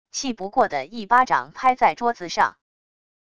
气不过的一巴掌拍在桌子上wav音频